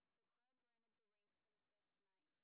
sp28_street_snr10.wav